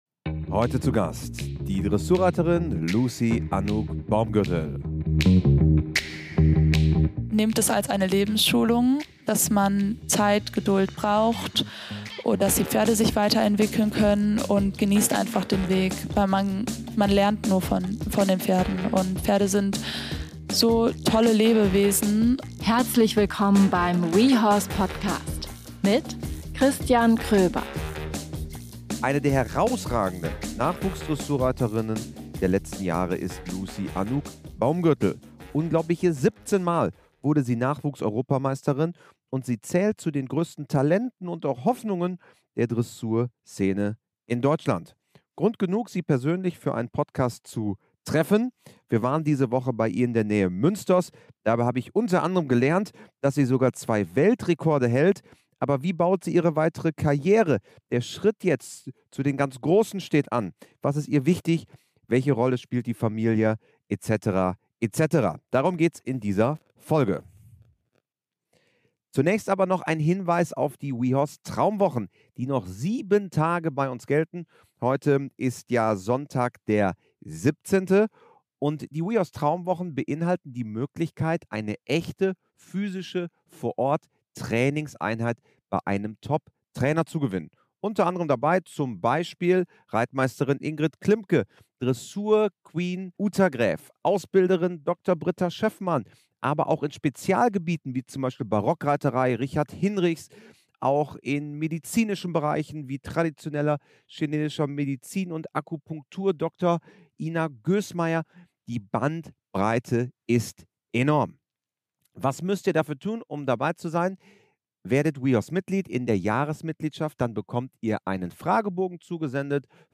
Ein Gespräch über Höchstleistungen im Sattel, echte Partnerschaft und die Liebe zum Pferdesport – viel Spaß dabei!